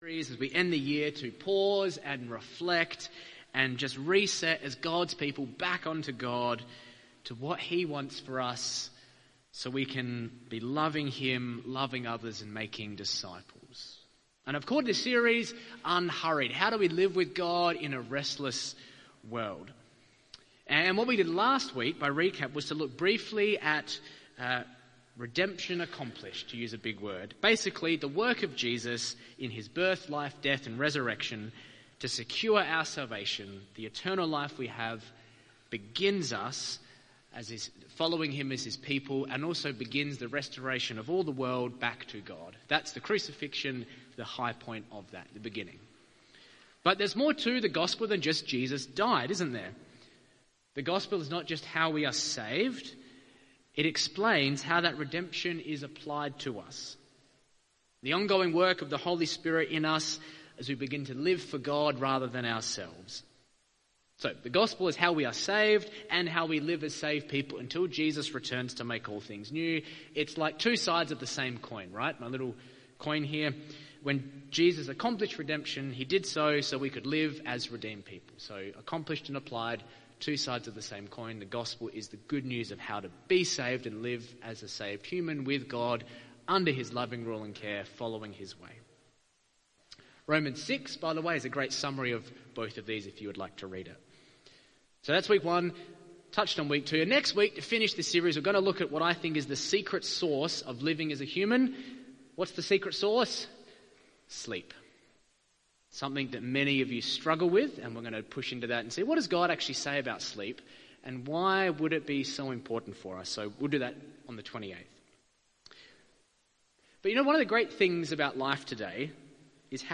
Talk Summary